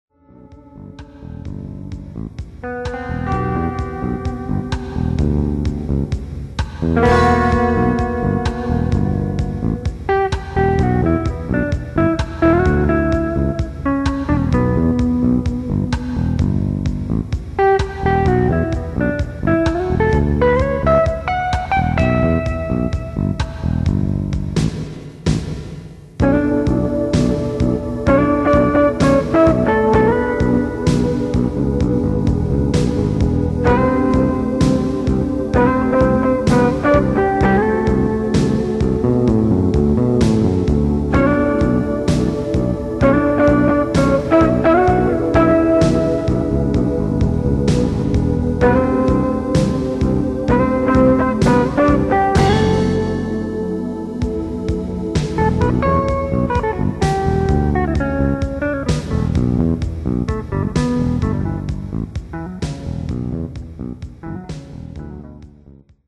なんか内向的なフュージョン曲ができた。
後にサイトでもUPした「陽気なBCL」という曲です。スティーブ・カーンの影響がモロに出ていますね。